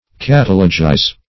Search Result for " catalogize" : The Collaborative International Dictionary of English v.0.48: Catalogize \Cat"a*lo*gize\, v. t. To insert in a catalogue; to register; to catalogue.